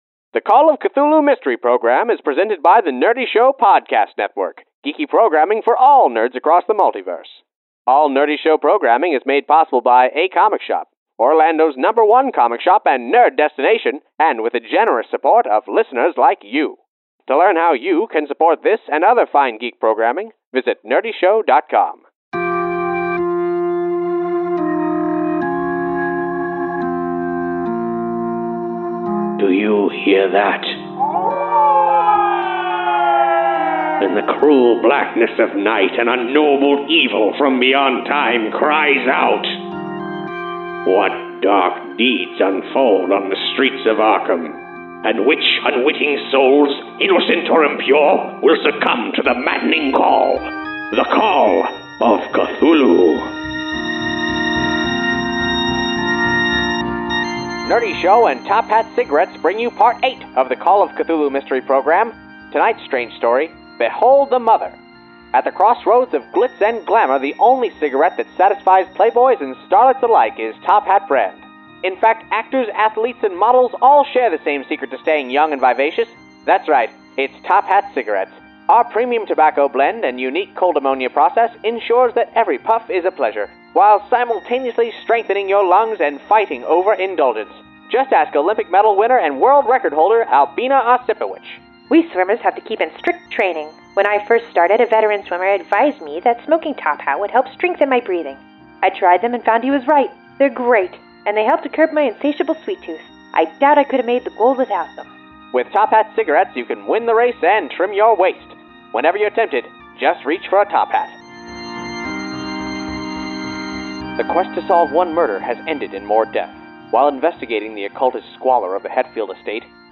The Call of Cthulhu Mystery Program is live tabletop roleplaying turned into a 1930s radio serial.